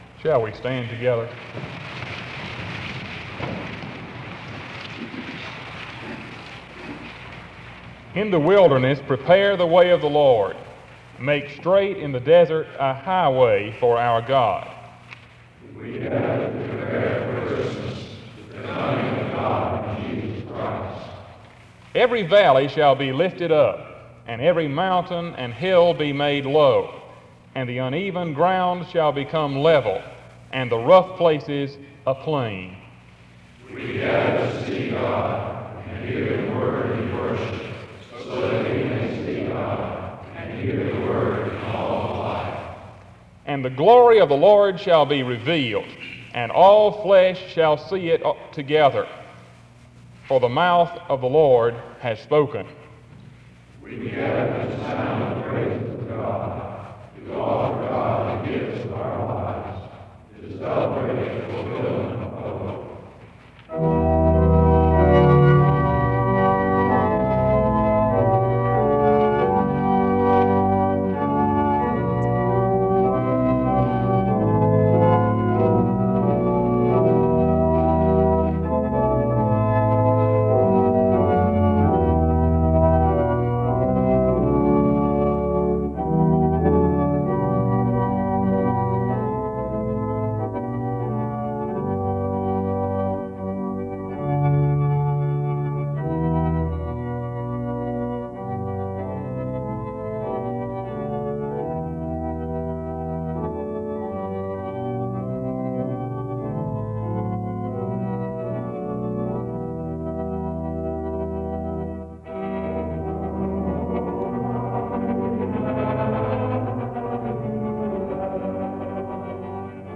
The service begins with a responsive reading from 0:00-1:03. O Come O Come Emmanuel plays from 1:03-5:34.
A prayer is offered from 5:46-6:45. Music plays from 7:12-9:12.
The service closes with music from 36:22-37:11.
SEBTS Chapel and Special Event Recordings